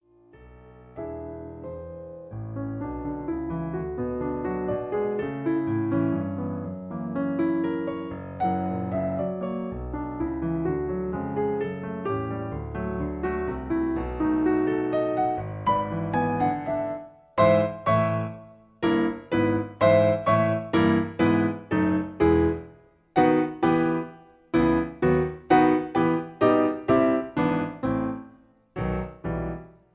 Piano Accompaniment